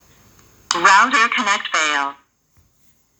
Прикрепил то, что говорит камера